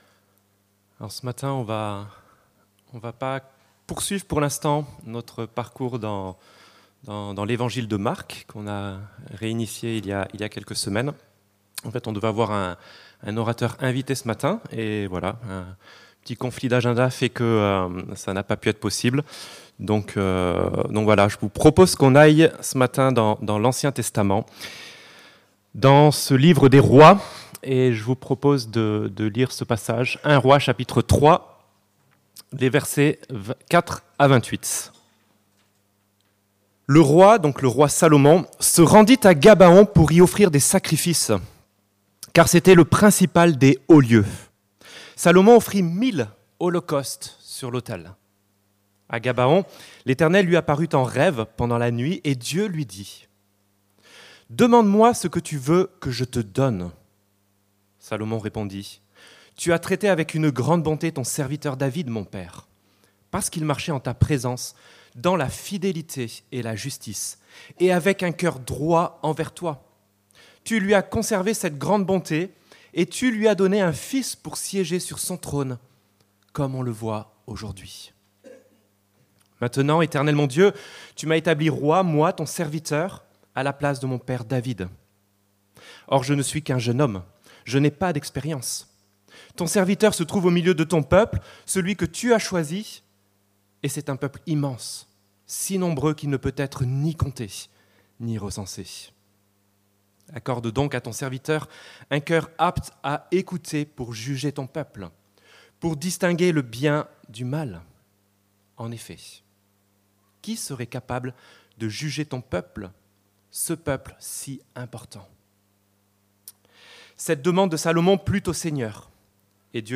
Un roi qui demande à Dieu la sagesse - Prédication de l'Eglise Protestante Evangélique de Crest sur le premier livre des Rois